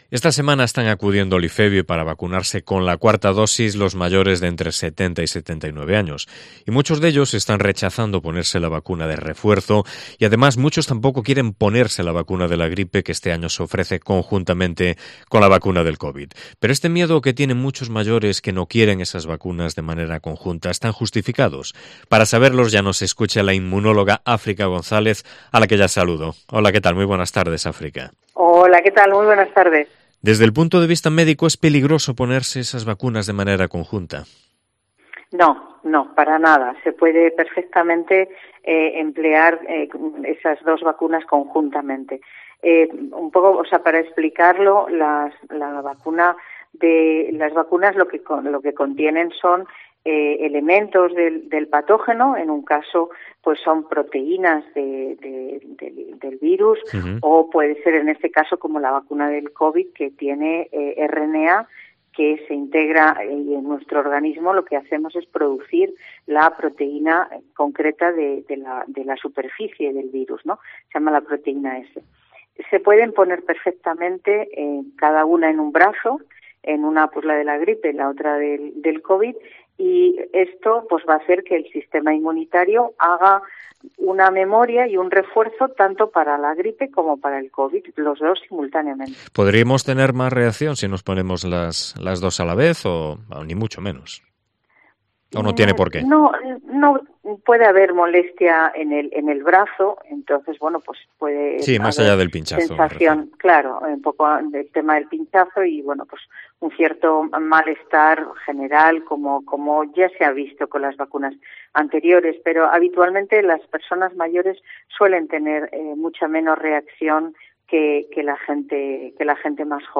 Vigo ENTREVISTA ¿Es seguro ponerse la vacuna del covid y de la gripe el mismo día?